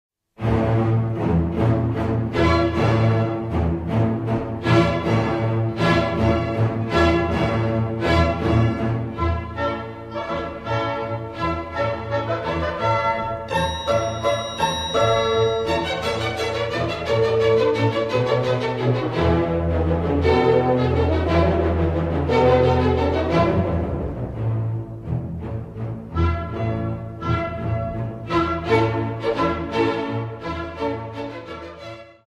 Lendler potraktowany jest w specyficzny sposób: dyrygent akcentuje tu dźwięki przypadające na słabą część taktu (na trzy), zaś te przypadające na mocną grane są ciszej.
Fascynuje też surowy, grubo ciosany dźwięk wiolonczel i kontrabasów: